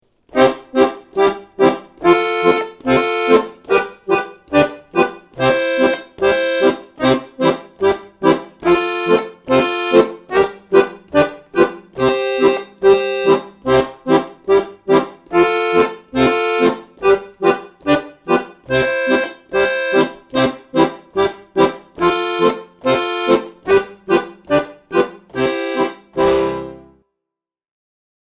acompanamento_parte_2.mp3